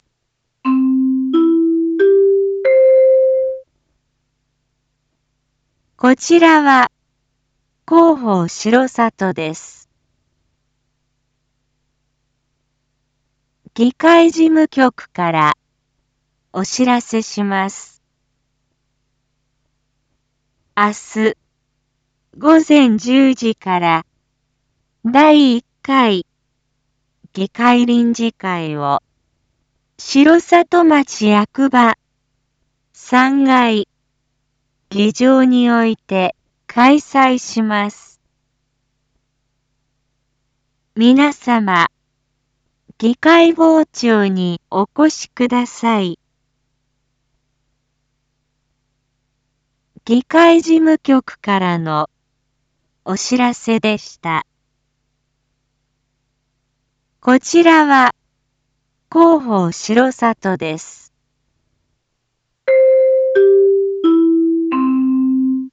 一般放送情報
Back Home 一般放送情報 音声放送 再生 一般放送情報 登録日時：2024-07-17 19:01:07 タイトル：第１回議会臨時会① インフォメーション：こちらは広報しろさとです。